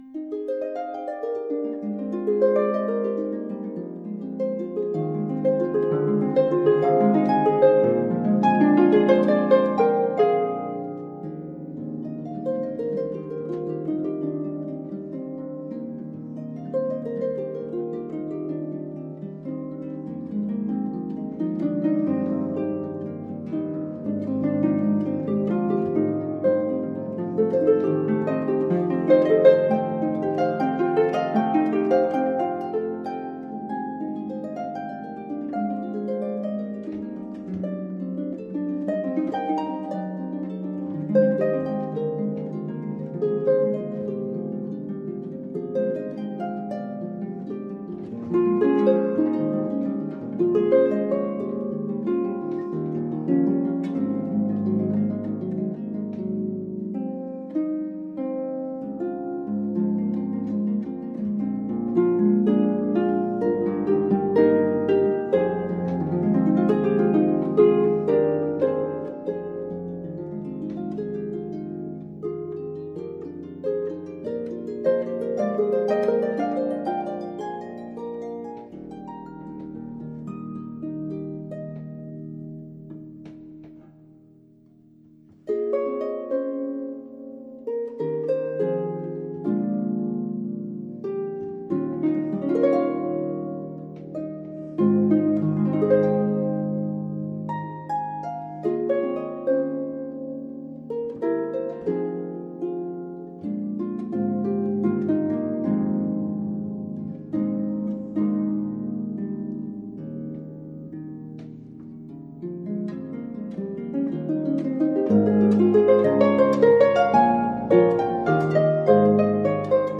DEBUSSY, arabesque n1 (Harpe) - MATISSE, fenetre ouverte a Collioure.mp3